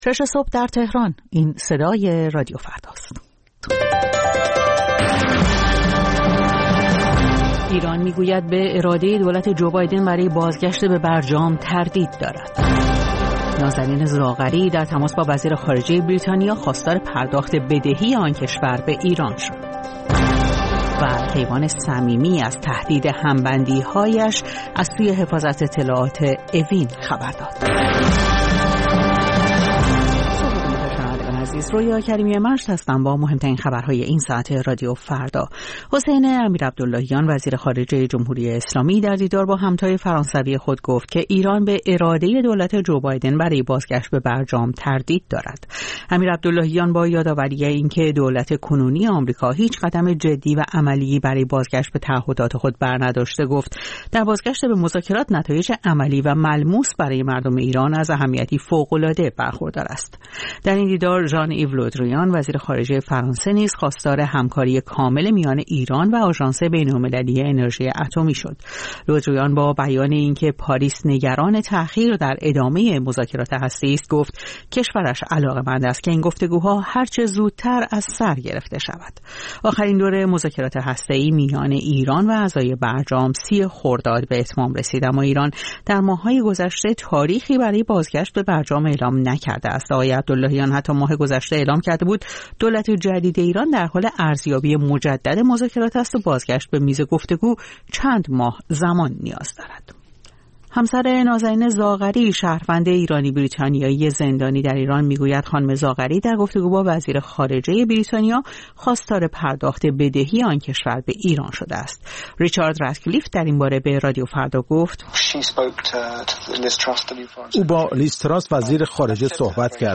سرخط خبرها ۶:۰۰